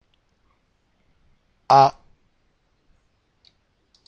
The big advantage is that each one of them, unlike some cases in the English language, just have one unique sound, regardless of how it is used.